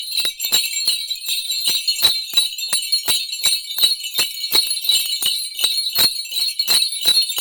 sleigh-bells.mp3